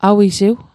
Pronunciation Guide: a·wi·siw